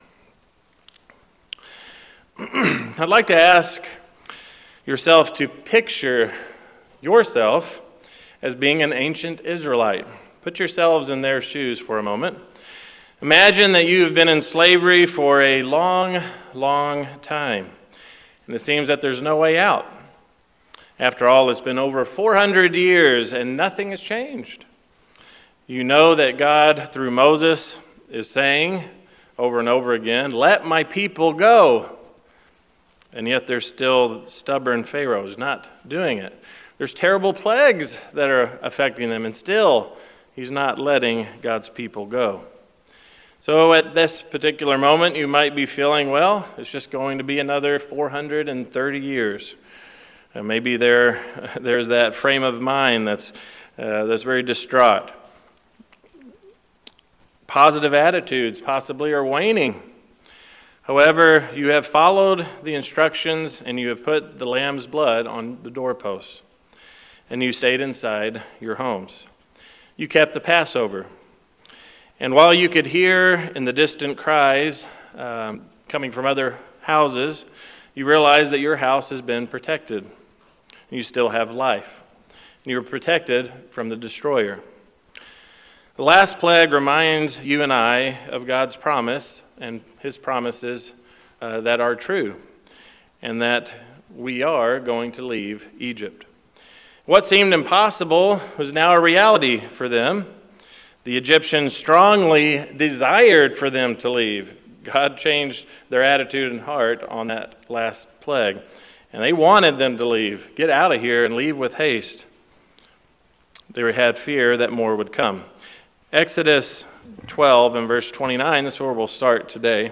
Apr23Sermon_TruthInTheInwardPart.mp3